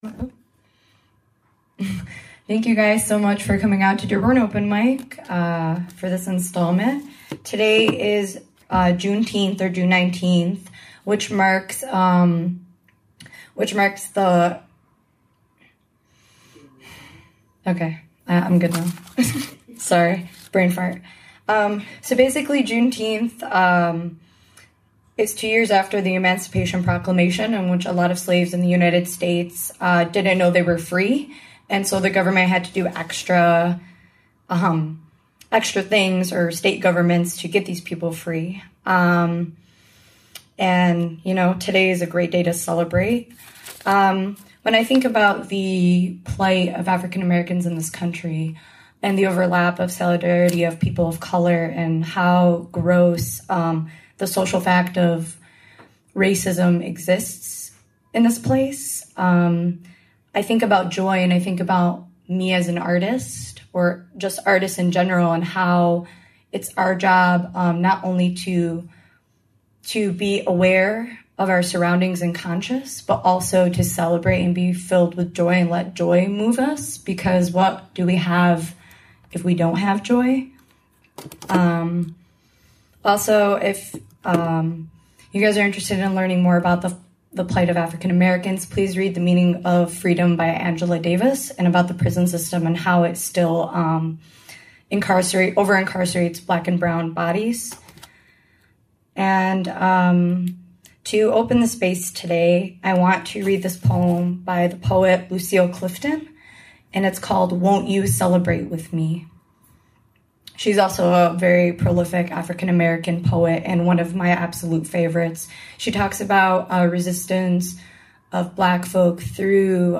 PODCAST: Dearborn Open Mic English 6-19-19